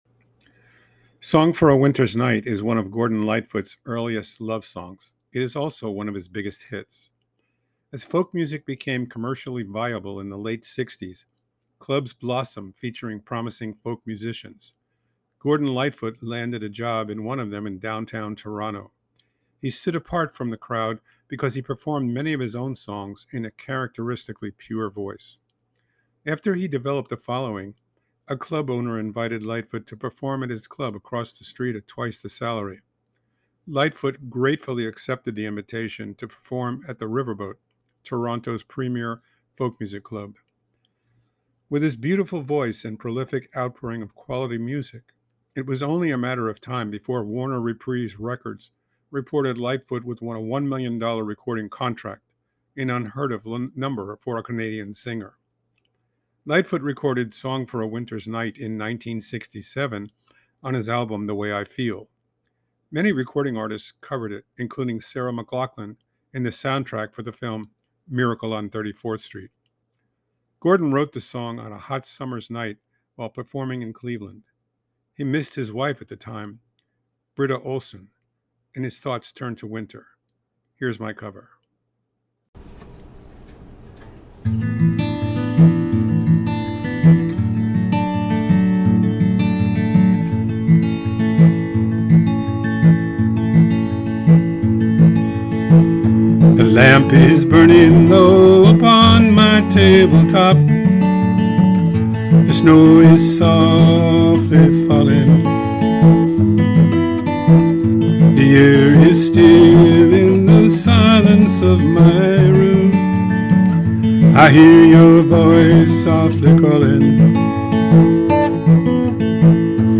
cover
love songs